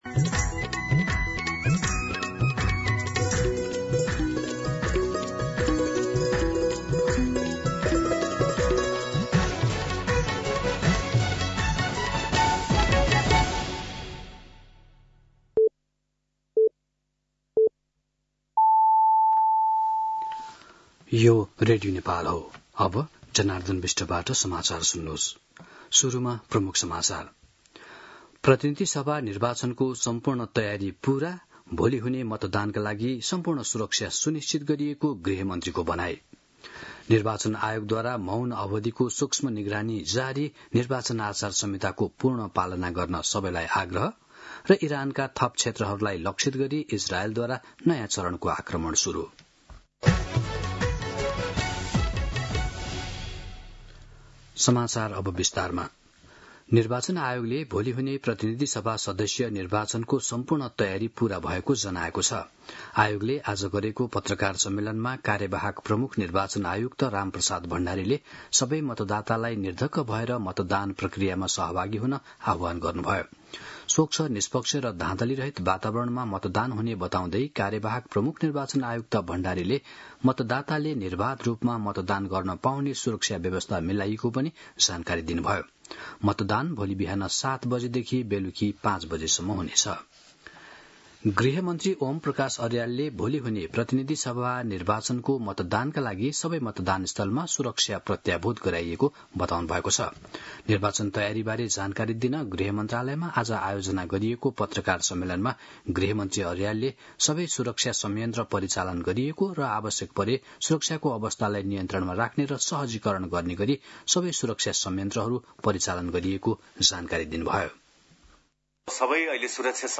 दिउँसो ३ बजेको नेपाली समाचार : २० फागुन , २०८२